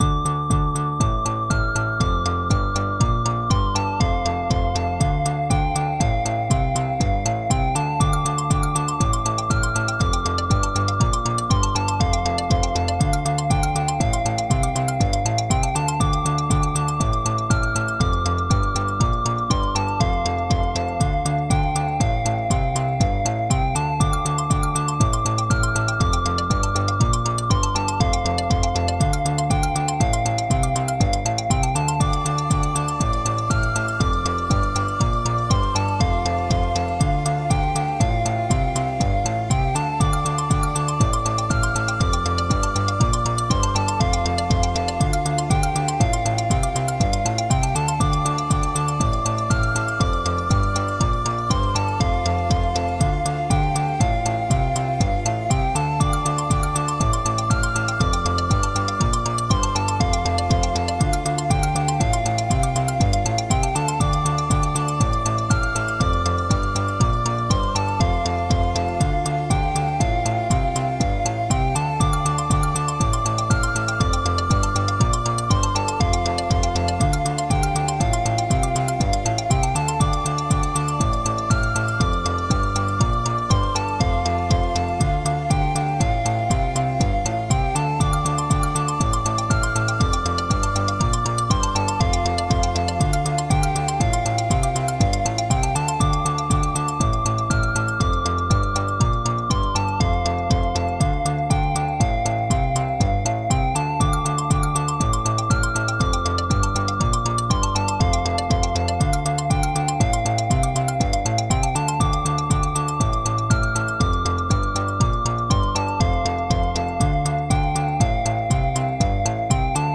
It's not super detailed any anyway. Just a few layers of sounds.
Music / Game Music